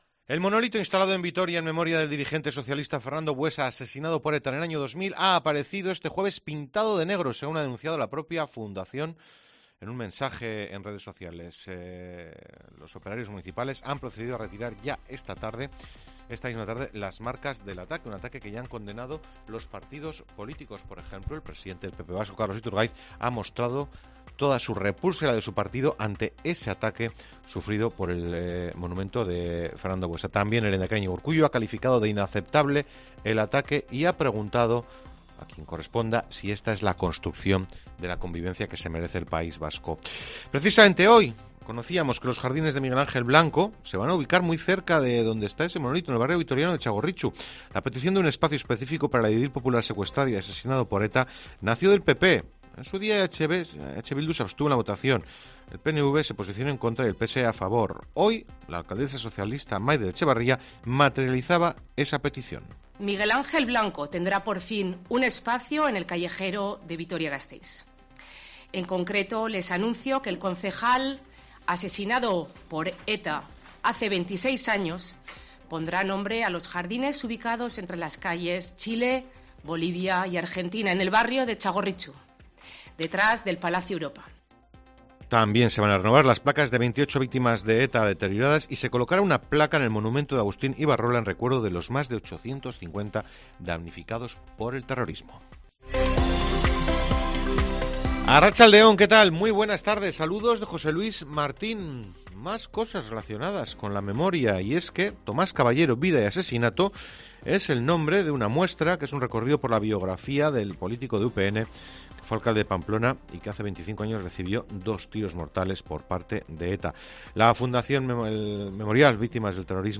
INFORMATIVO TARDE COPE EUSKADI 05/10/2023